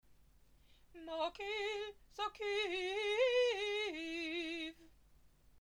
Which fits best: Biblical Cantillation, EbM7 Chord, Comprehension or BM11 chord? Biblical Cantillation